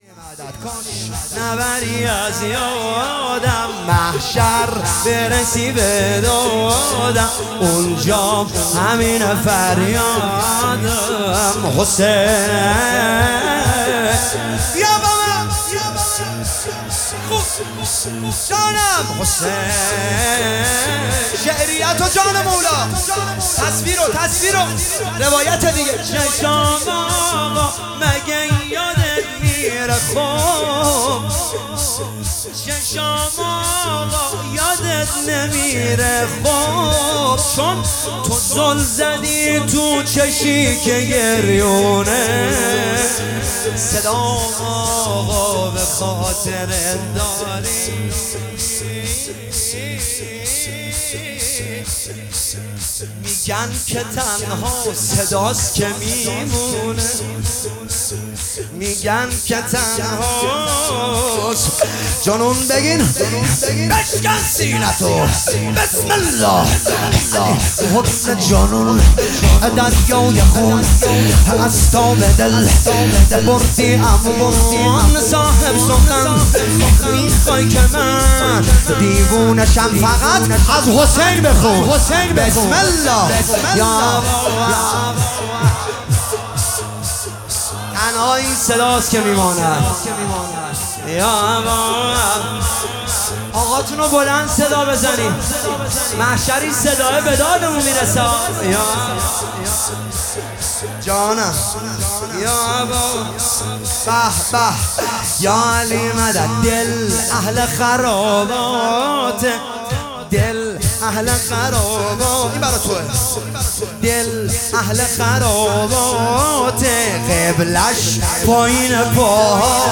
شهادت امام جواد (ع) 1404